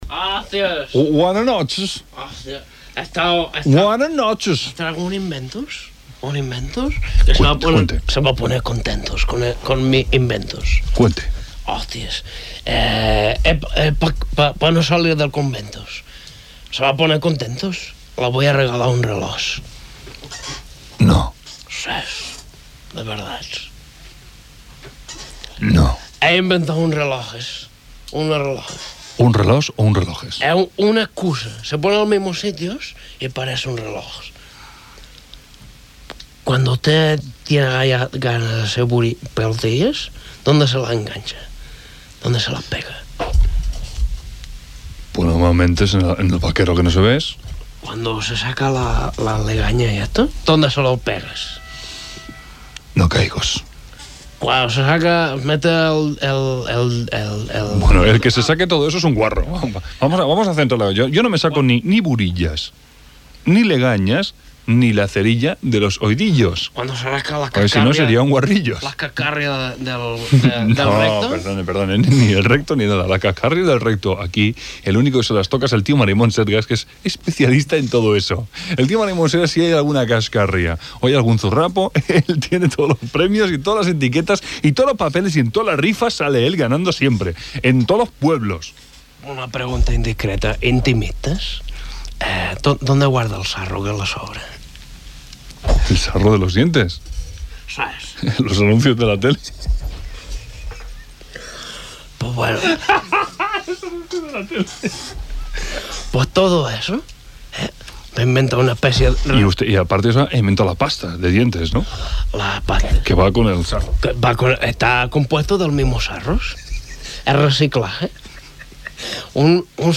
El personatge del programa anomenat "Doctos" explica al presentador un invent semblant a un rellotge.
Entreteniment